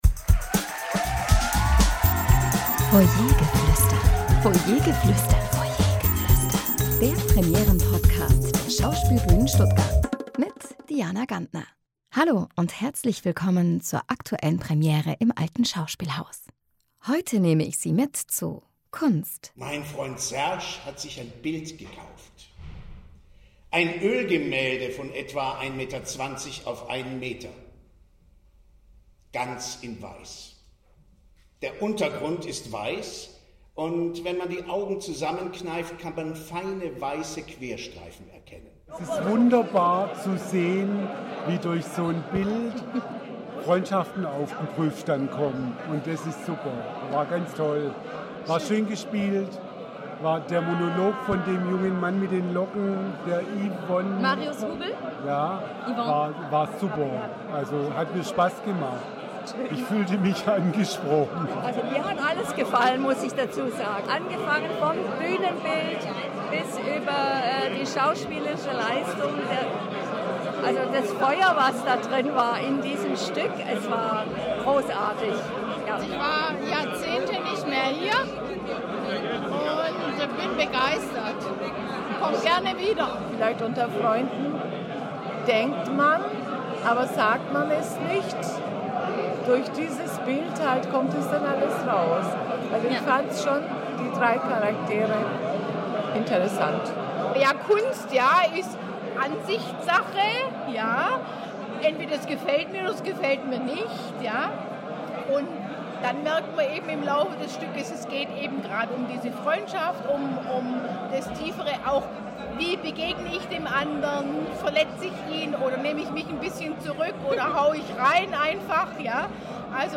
Zuschauerstimmen zur Premiere von “Kunst”